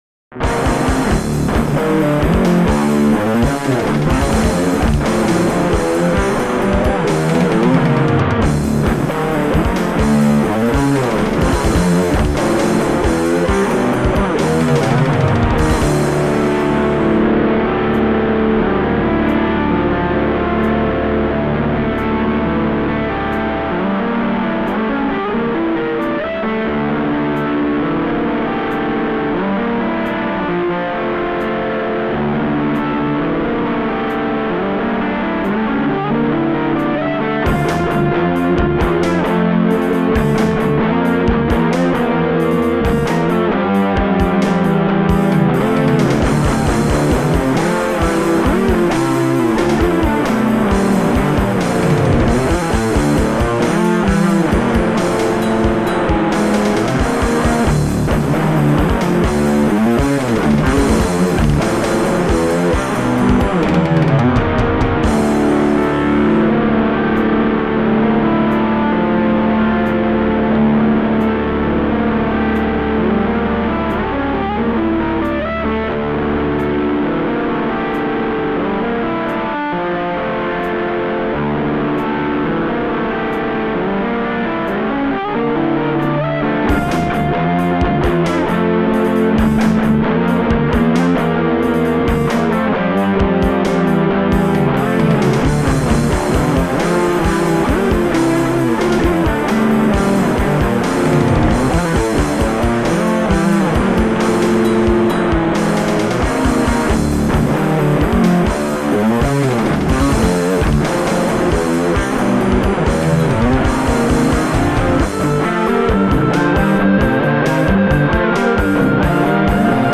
w warunkach domowych.
instrumenalny